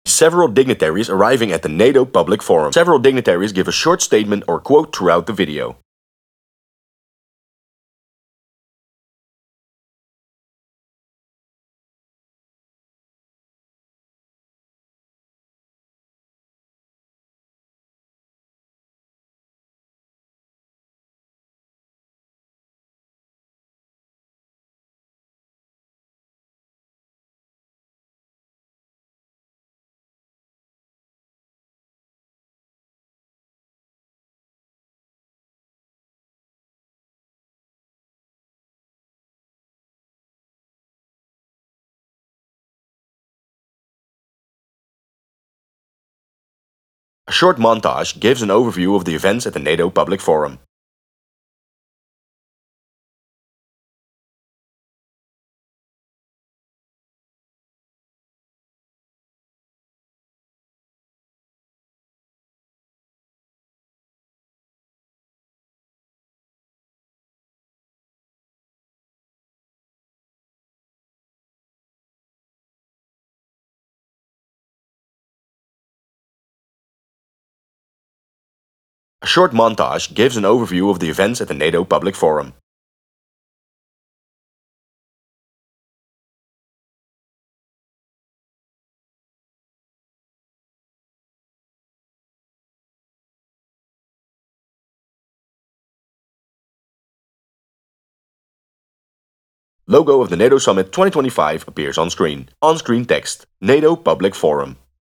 *Inpspiring music plays*
The video starts with the hosts explaining what happens at the NATO Public Forum.
Several dignitaries give a short statement or quote throughout the video.